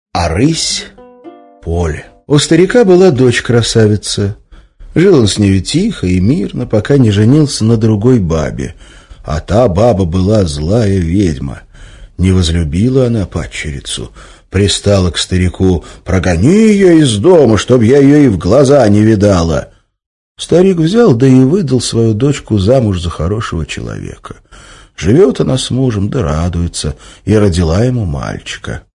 Аудиокнига Арысь-поле | Библиотека аудиокниг